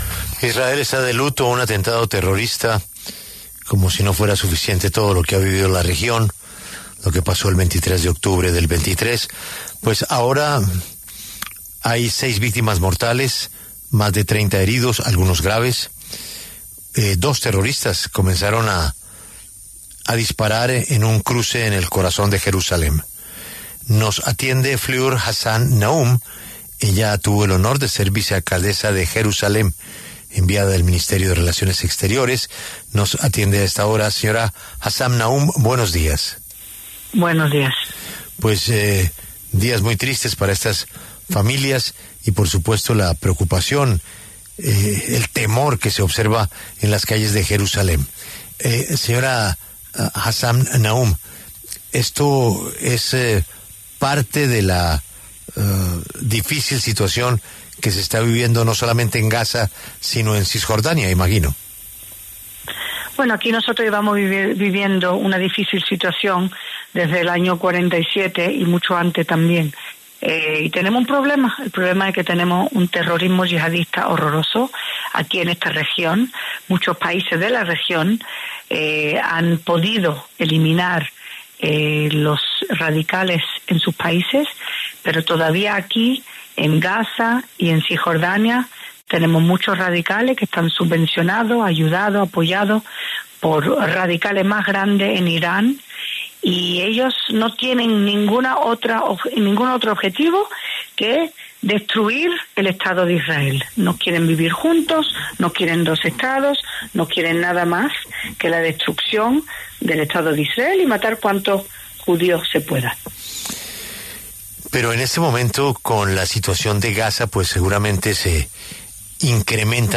Fleur Hassan Nahoum, exvicealcaldesa de Jerusalén, habló en La W a propósito del atentado terrorista en la capital de Israel que dejó seis víctimas mortales.